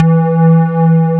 SQUAREE4.wav